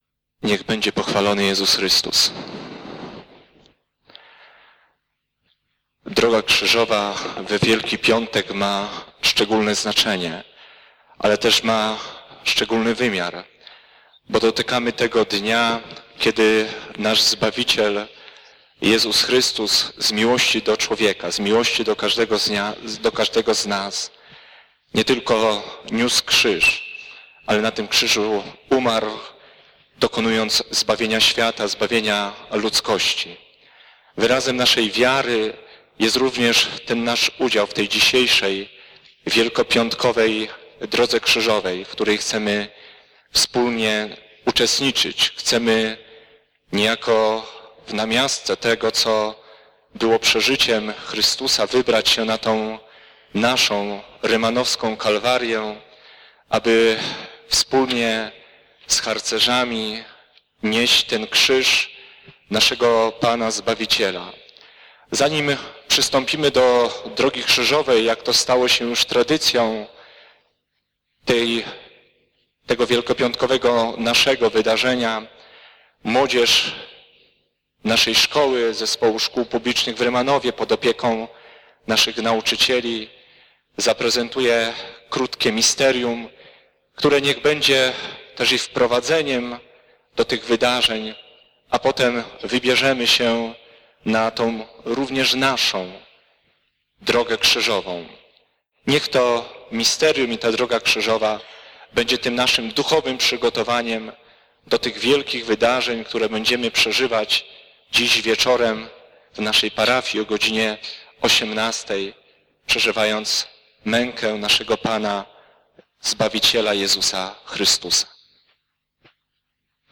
W godzinach przedpołudniowych, tradycyjnie w naszej parafii odbywa się nabożeństwo Drogi Krzyżowej.